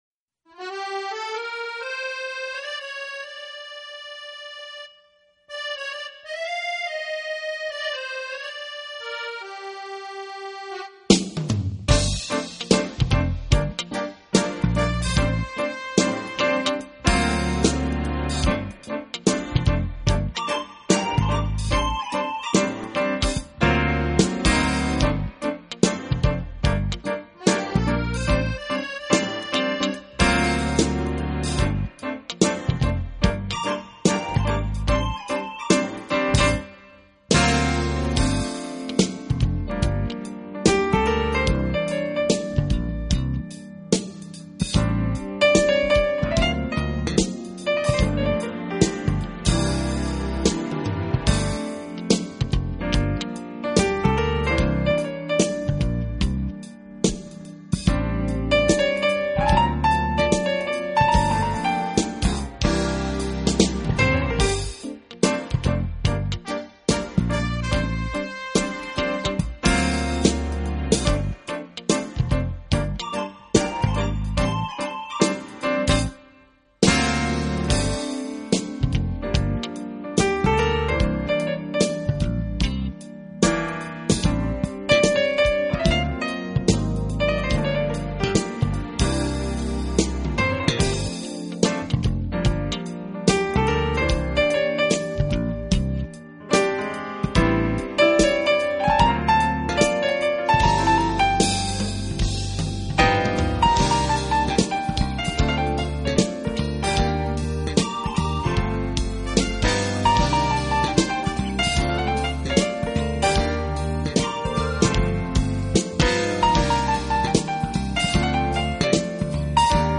【爵士钢琴】
音乐类型：Jazz
路做出了定义，那就是和Soul-jazz紧密结合的现代爵士音乐。
alto saxophone
bass
Recorded at Digital Recorders, Nashville, Tennessee.